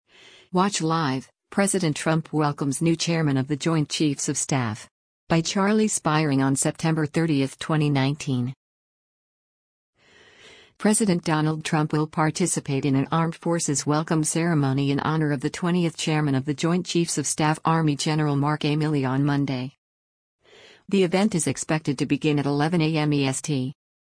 President Donald Trump will participate in an Armed Forces Welcome Ceremony in honor of the twentieth chairman of the Joint Chiefs of Staff Army Gen. Mark A. Milley on Monday.